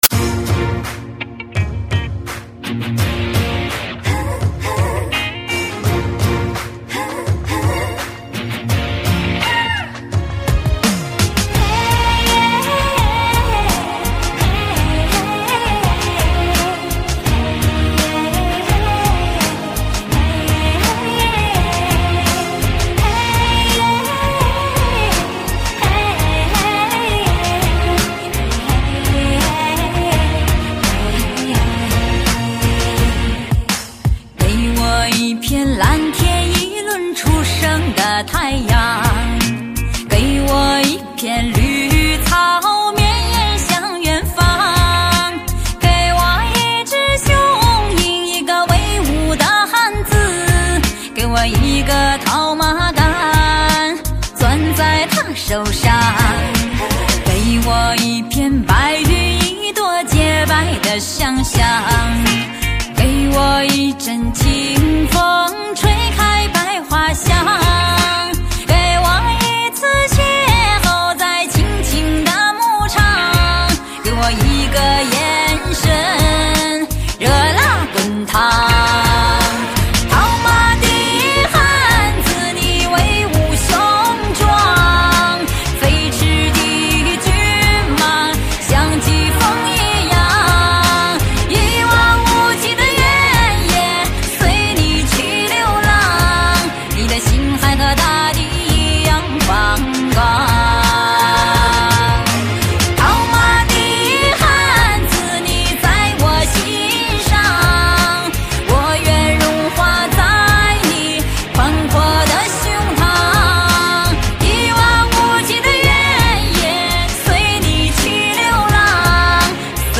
草原时尚新民歌天后